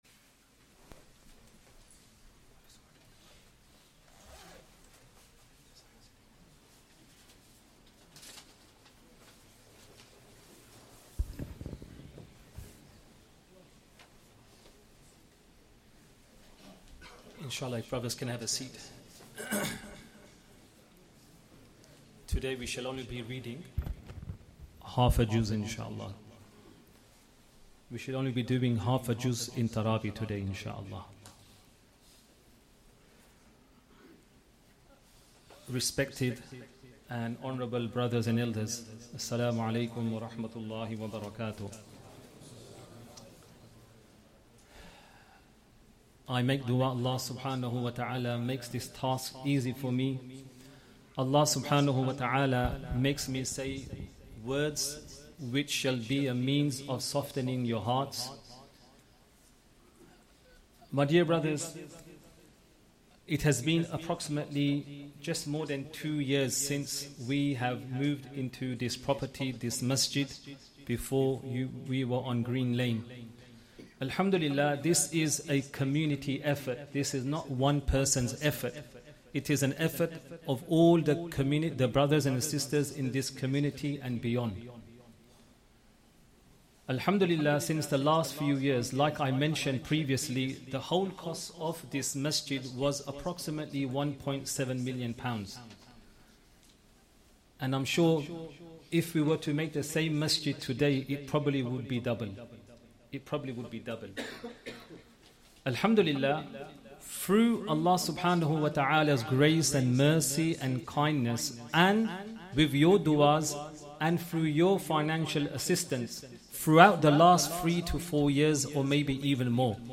1st Tarawih prayer - 27th Ramadan 2024